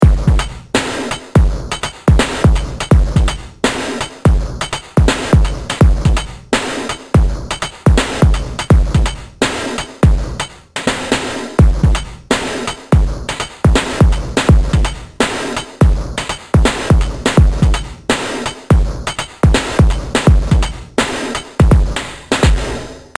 Specjalnie dla fanów rytmów klasyczny EDM bit, tym razem w tempie 83.
Tym razem w stylu klasyczny EDM, bity zawierają główny rytm wraz z przejściami. Nagrane i zmiksowane w moim studiu.
Duża dynamika, sami sprawdźcie.